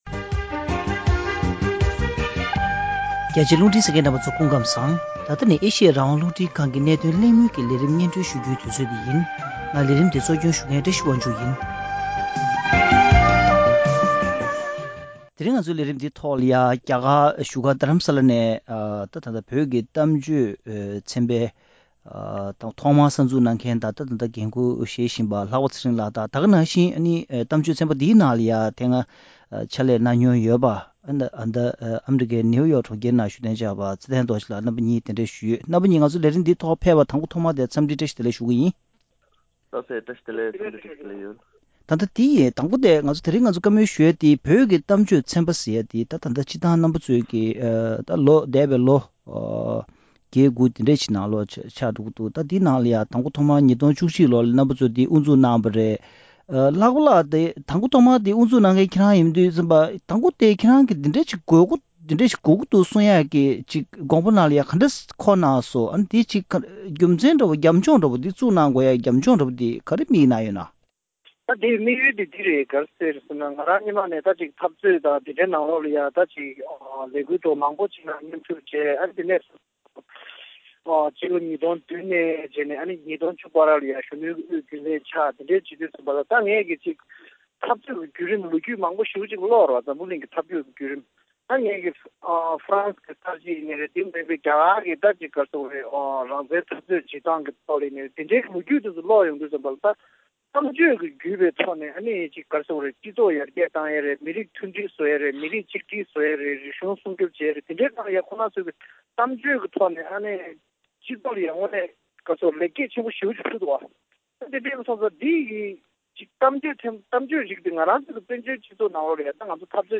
བོད་ཀྱི་གཏམ་བརྗོད་དང་མཚར་གཏམ་ལས་རིམ་གྱིས་བོད་དོན་འཐབ་རྩོད་དང་སྤྱི་ཚོགས་ཐོག་ཤུགས་རྐྱེན་ཞེས་པའི་བརྗོད་གཞིའི་ཐོག་གླེང་མོལ།